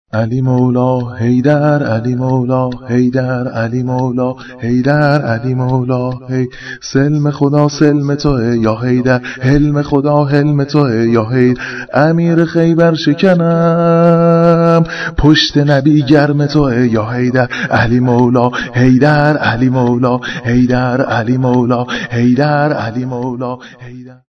(شور)